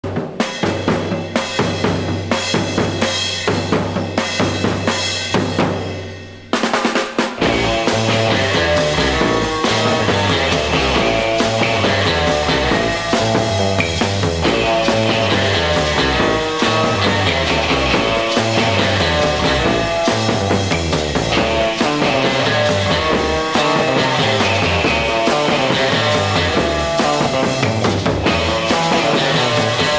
Garage